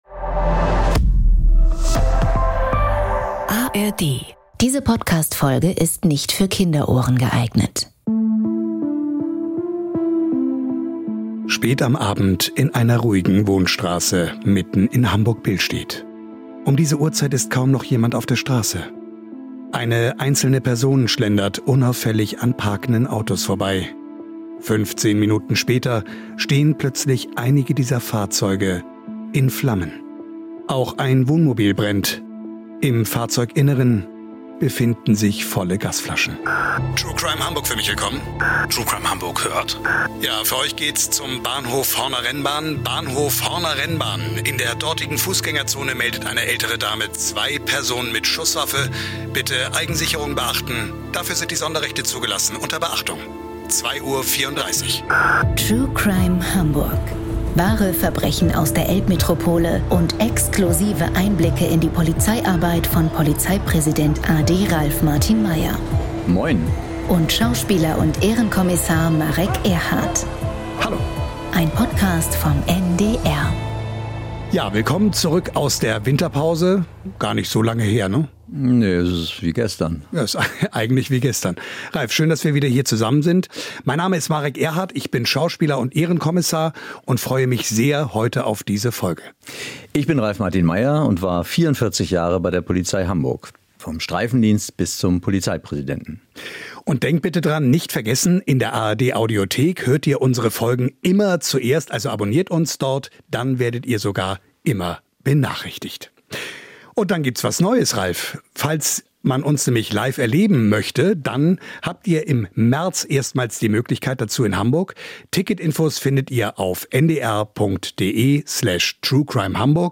Hamburgs Polizeipräsident a.D. Ralf Martin Meyer erklärt, warum Ermittler immer wieder Geduld, Spürsinn und Nervenstärke beweisen müssen.